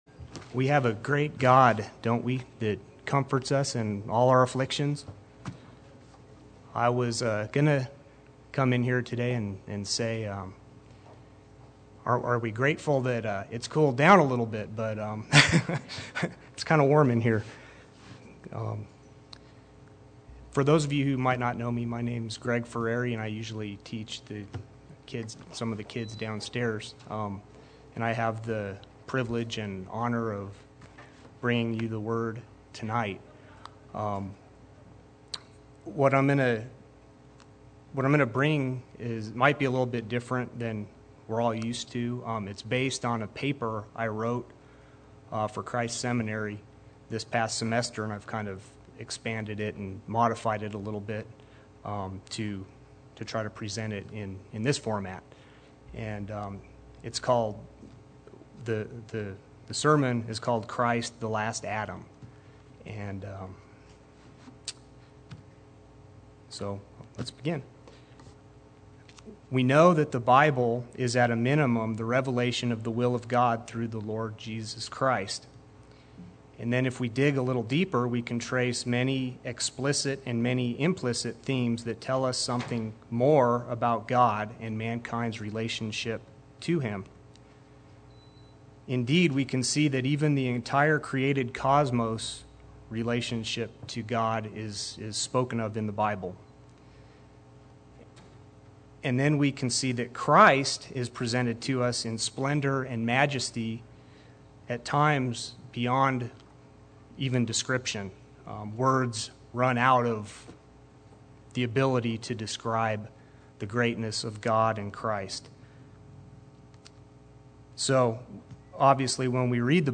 Play Sermon Get HCF Teaching Automatically.
the Last Adam Wednesday Worship